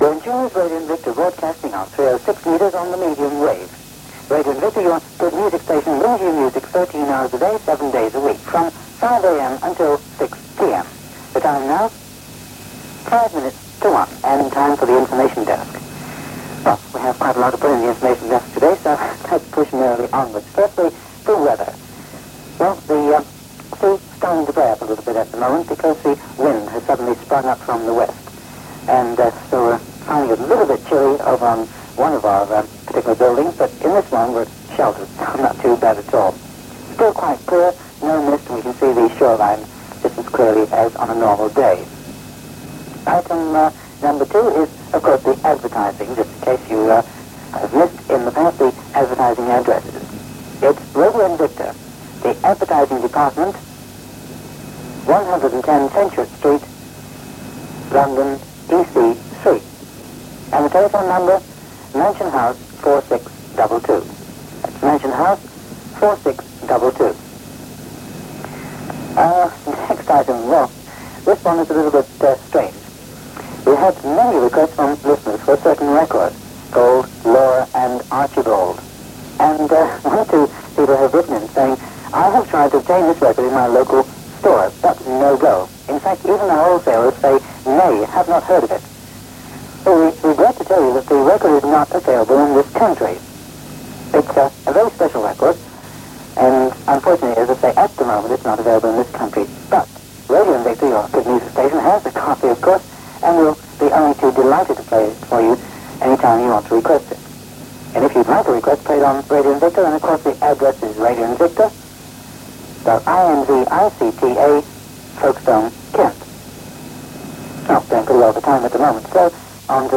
click to hear audio Another unidentified DJ on Radio Invicta with the Information Desk feature, broadcast each weekday at 12.55pm. Recording from the CD ‘The Things You've Never Heard Before Volume 1’